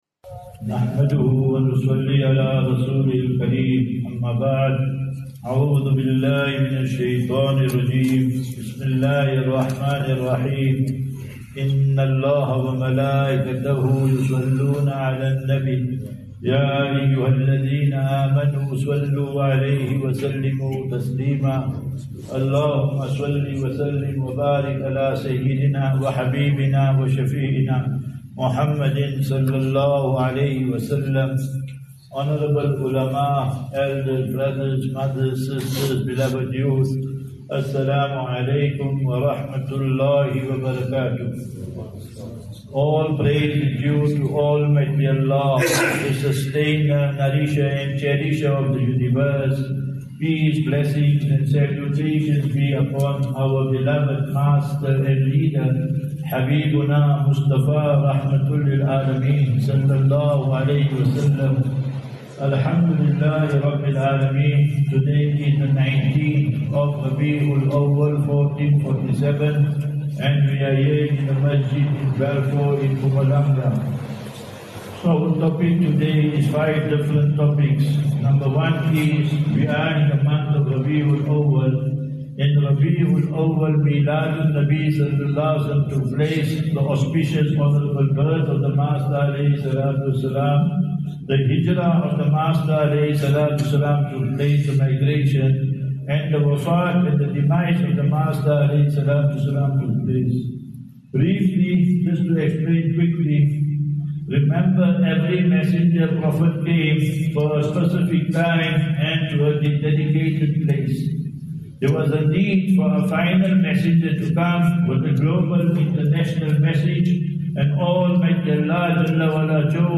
12 Sep 12 September 25 - Jumu'ah Lecture at Balfour Masjid